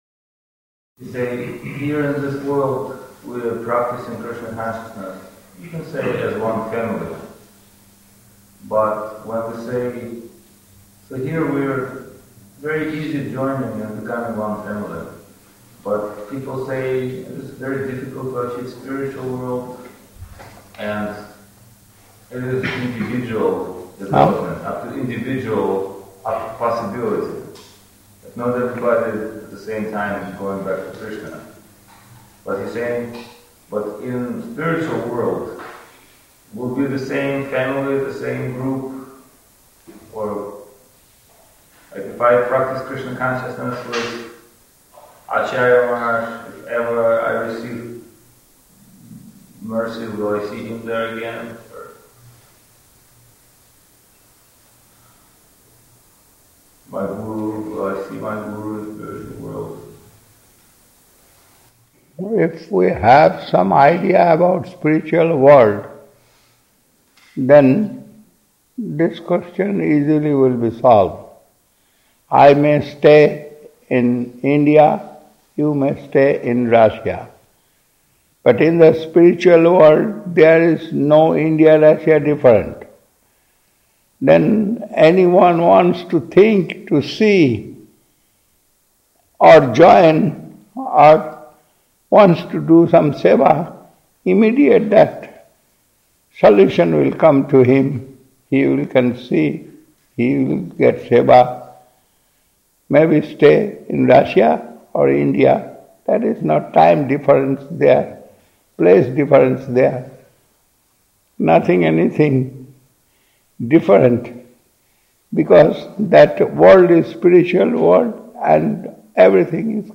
Place: Sri Chaitanya Saraswat Math Saint-Petersburg